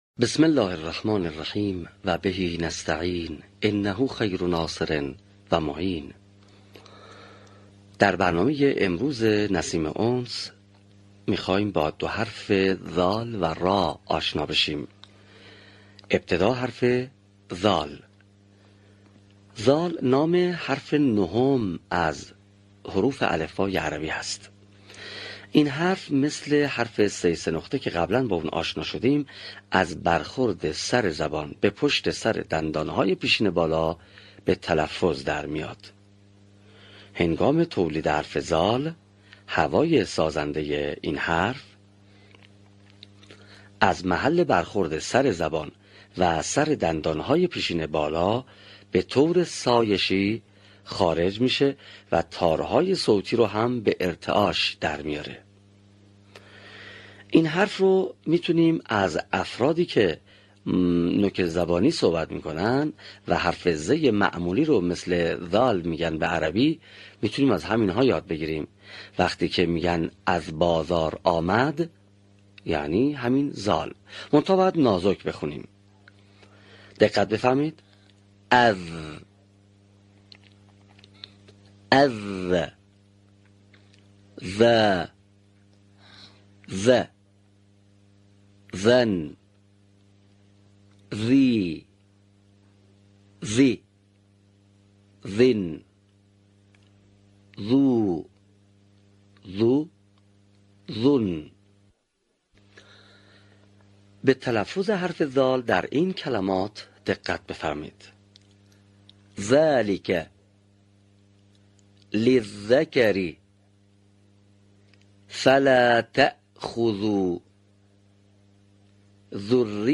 صوت | تلفظ صحیح حروف «ذ» و «ر»
به همین منظور مجموعه آموزشی شنیداری (صوتی) قرآنی را گردآوری و برای علاقه‌مندان بازنشر می‌کند.